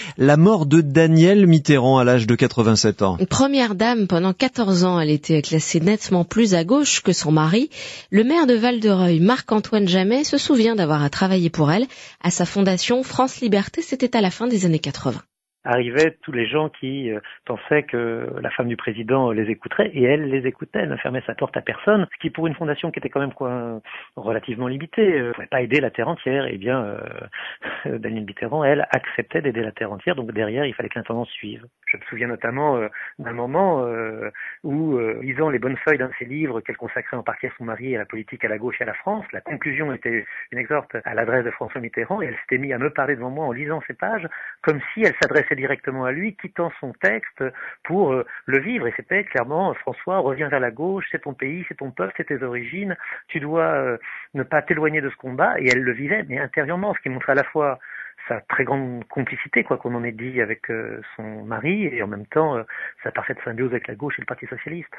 Marc-Antoine Jamet évoque, dans une interview donnée à France Bleu Haute-Normandie, deux souvenirs de Danielle Mitterrand vécus au moment où il travaillait à ses côtés à la Fondation France Libertés.